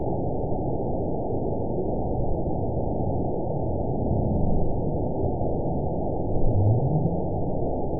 event 921773 date 12/18/24 time 23:20:53 GMT (11 months, 2 weeks ago) score 9.62 location TSS-AB02 detected by nrw target species NRW annotations +NRW Spectrogram: Frequency (kHz) vs. Time (s) audio not available .wav